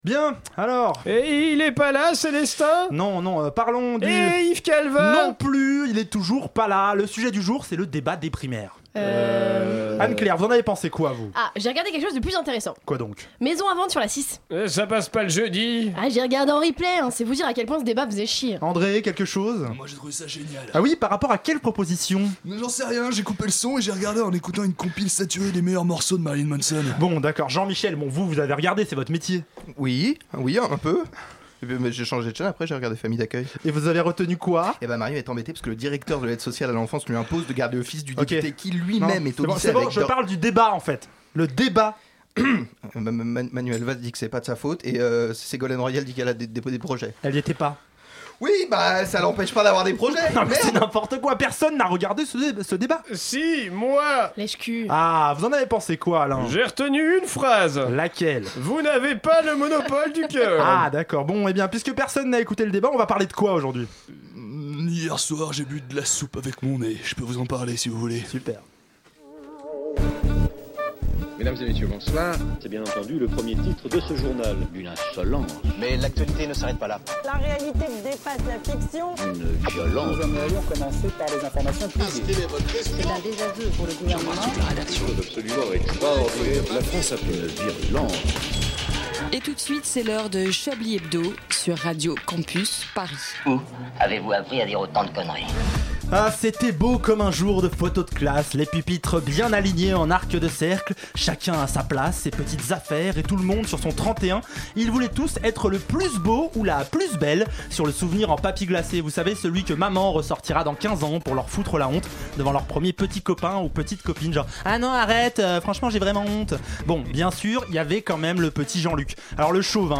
Mais vous retrouvez aussi une équipe de faire valoir d'exception.